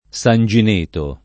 Sangineto [ S an J in % to ]